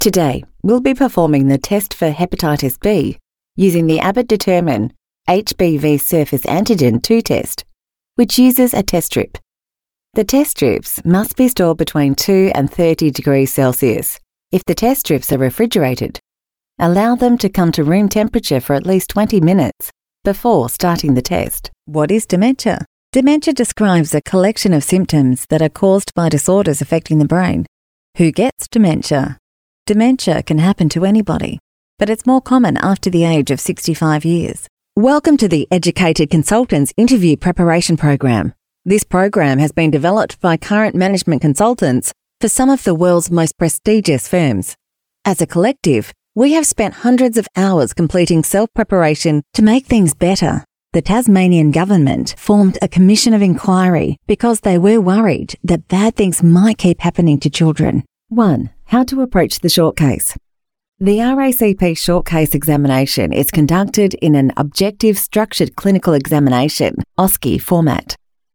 AU ENGLISH
Voice Sample: eLearning Demo